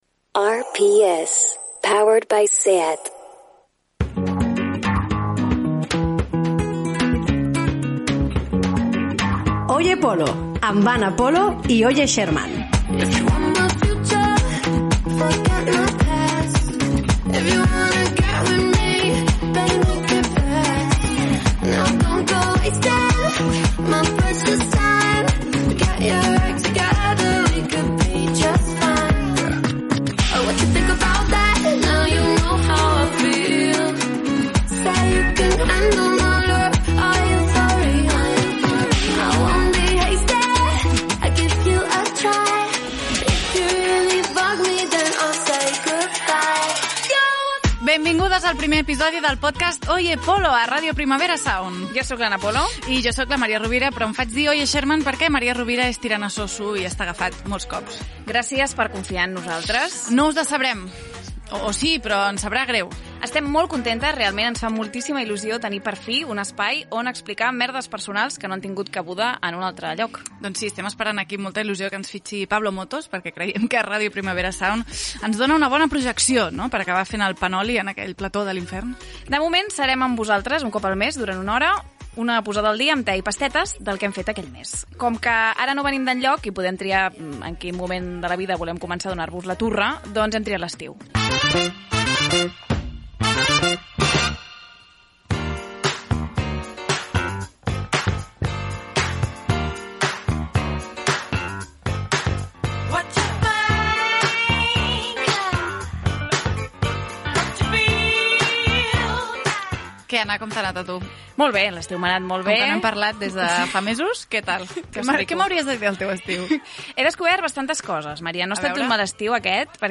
Identificaió de la ràdio, publicitat, careta del programa